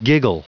Prononciation du mot giggle en anglais (fichier audio)
Prononciation du mot : giggle